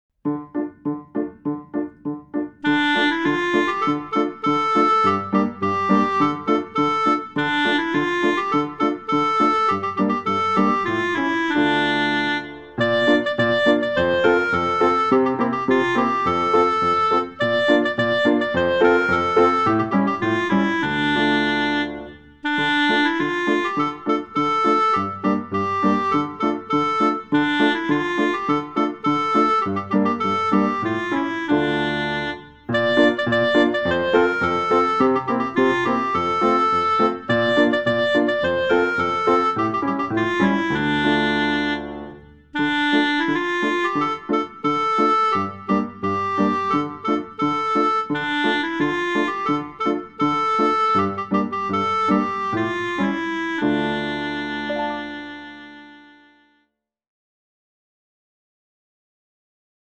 P36 n°2 rythme parlé puis en chantant : 22 petit ane22 petit ane (10.1 Mo)